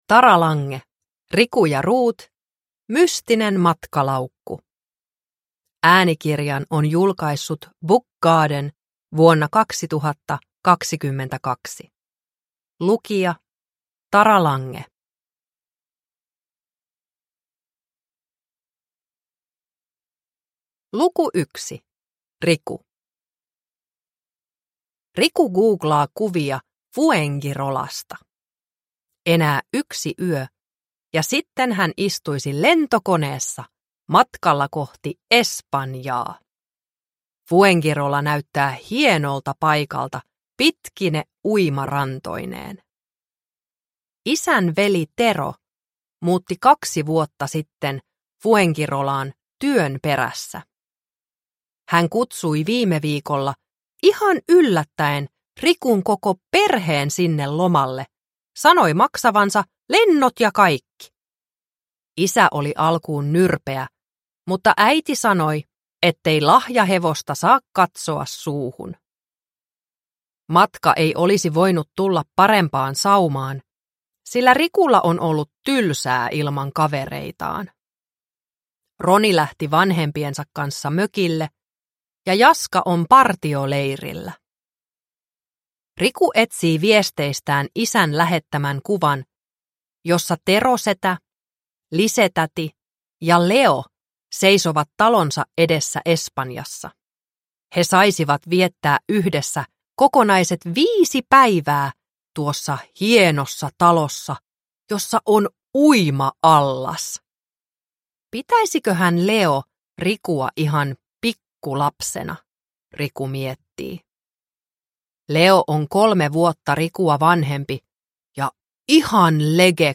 Riku ja Ruut - Mystinen matkalaukku – Ljudbok – Laddas ner